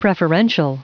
Prononciation du mot preferential en anglais (fichier audio)
preferential.wav